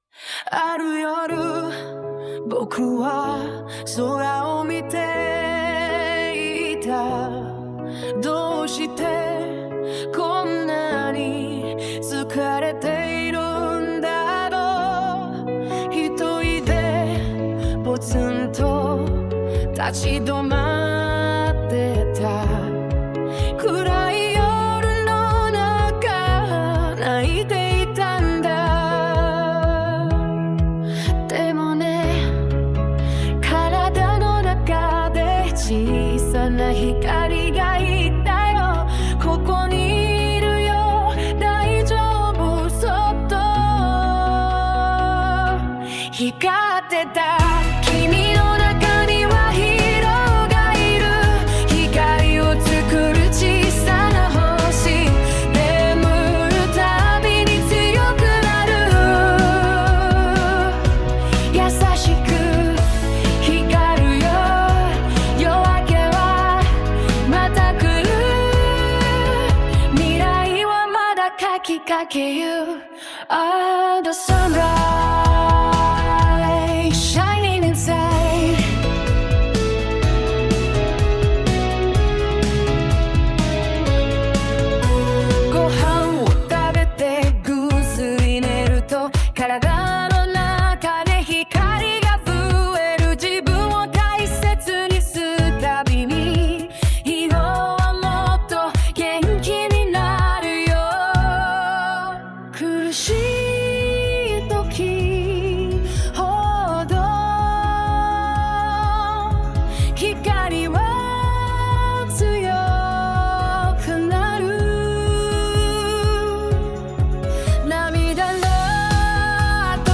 Original Song 『君の中のヒーロー』 絵本（2026年春 発刊予定） 『ぼくの体の中にヒーローがいた！』